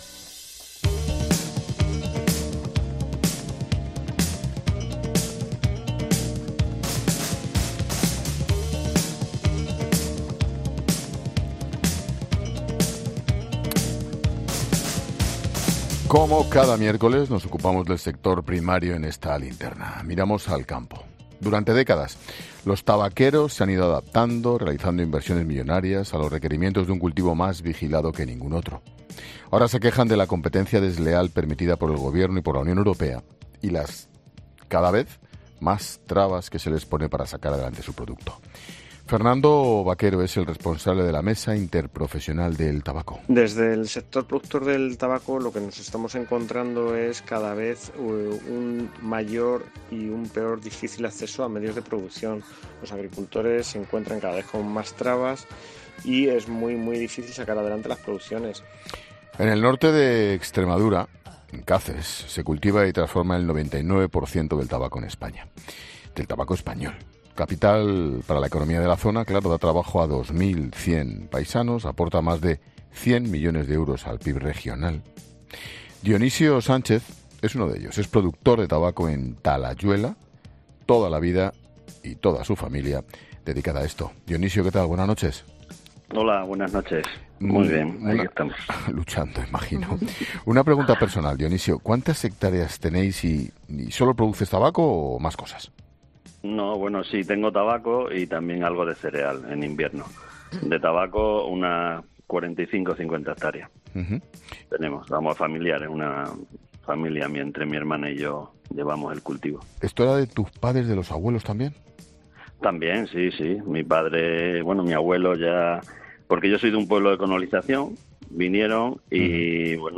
Un miércoles más hablamos sobre el sector primario en La Linterna y hoy, concretamente, miramos al campo .